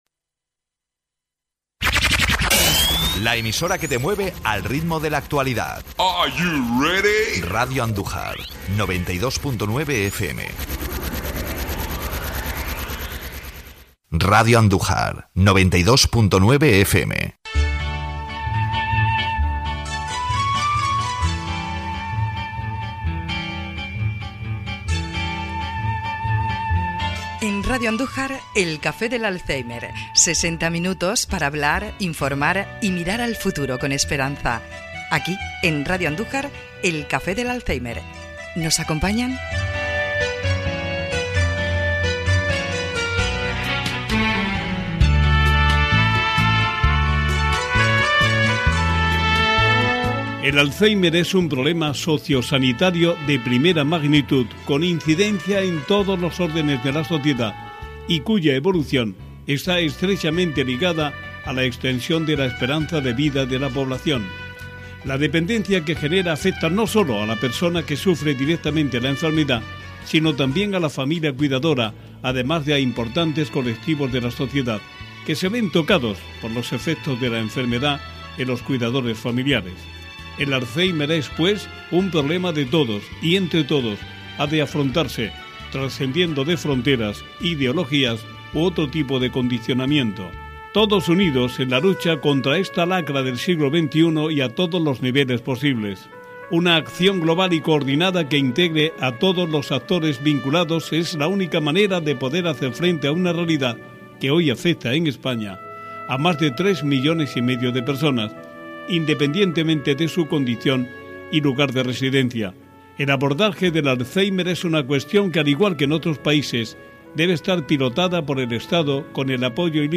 Programa de Radio sobre el Alzheimer y otras Demencias BLAS INFANTE, S/N. Protección de Datos). 3.